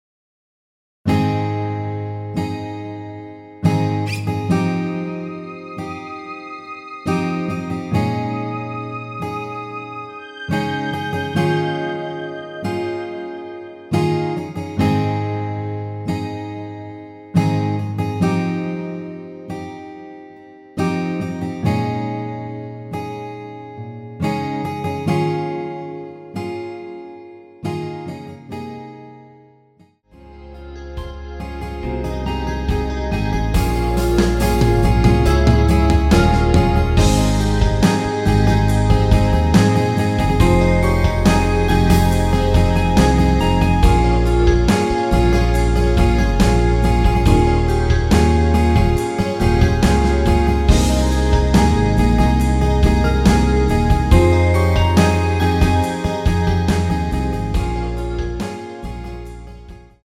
원키에서(+5)올린 MR입니다.
Ab
앞부분30초, 뒷부분30초씩 편집해서 올려 드리고 있습니다.
중간에 음이 끈어지고 다시 나오는 이유는